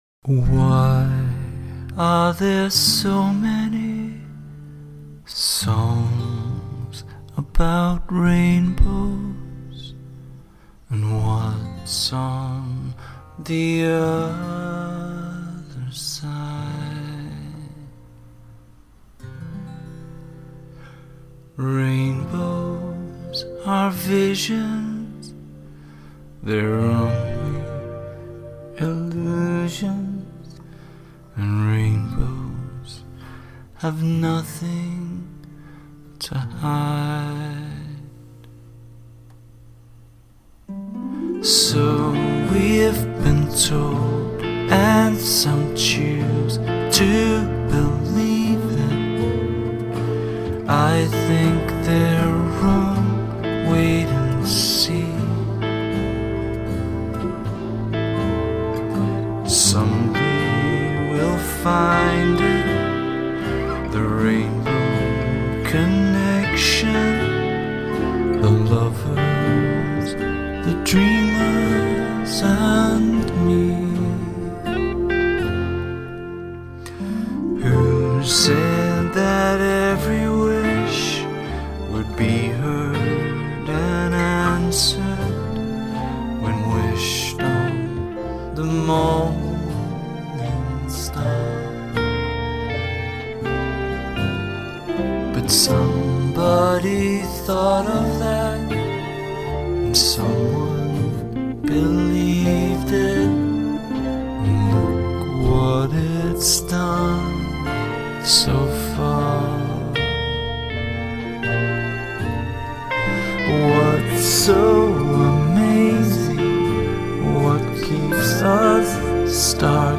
close-miked, crooning